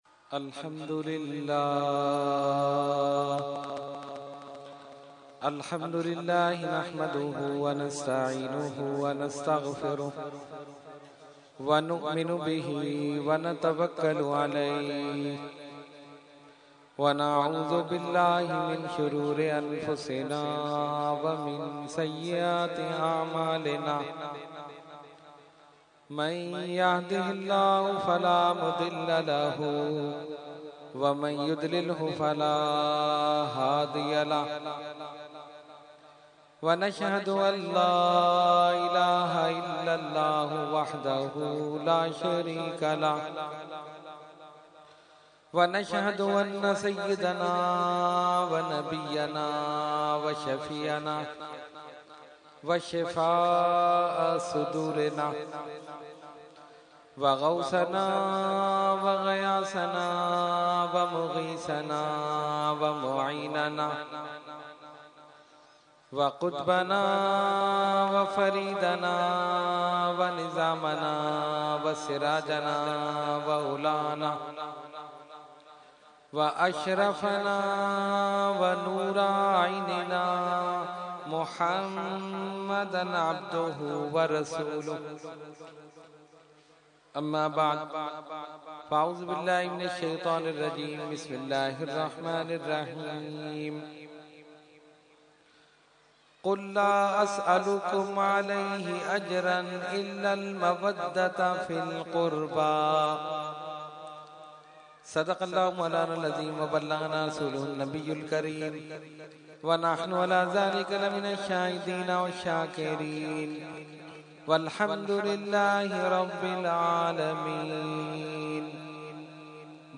Category : Speech | Language : UrduEvent : Muharram ul Haram 2014